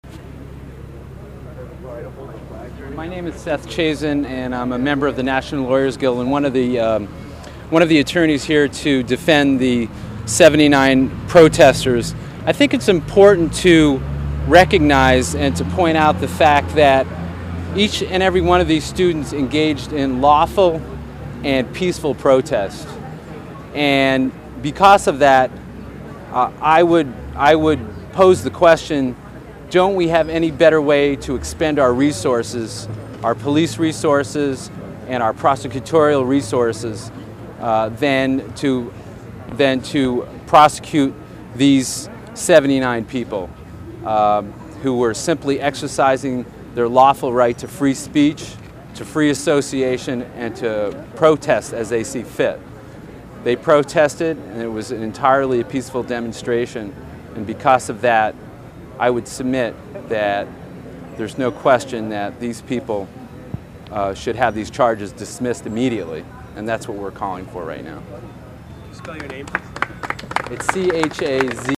Statements from NLG and SJP from a 4/30 press conference held in front of the Berkeley courthouse